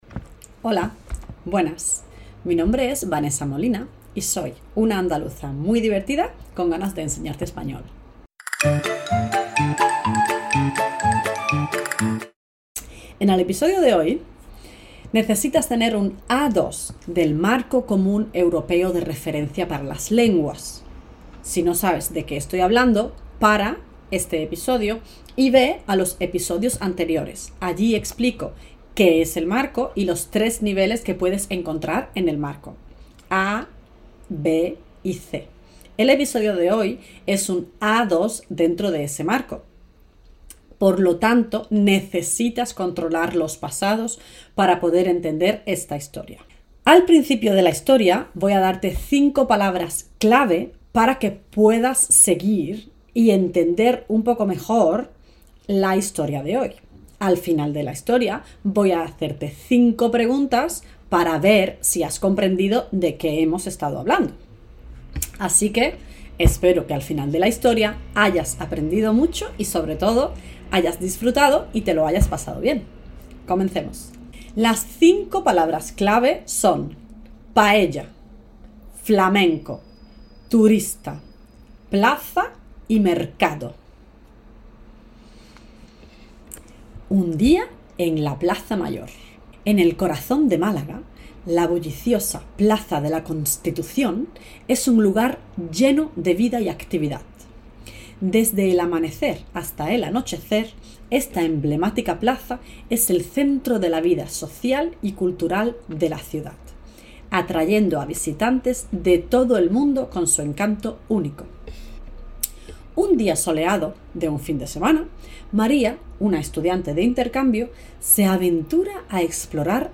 Escucha comprehensiva.